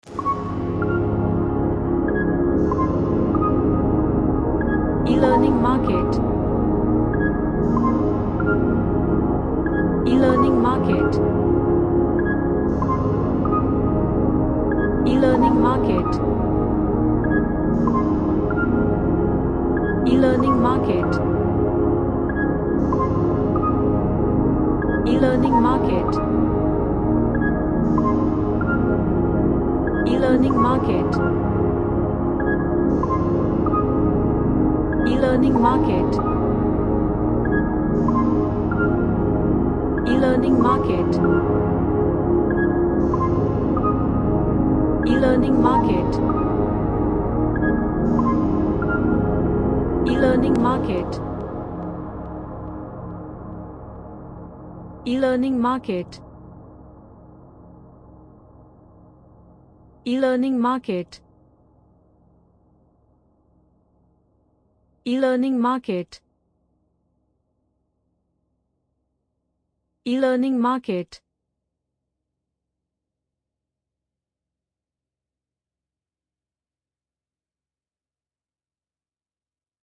A Progressive relaxing vibed track with pads and piano.
Relaxation / Meditation